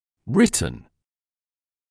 [‘rɪtn][‘ритн]написанный; пр.прош.вр. от to write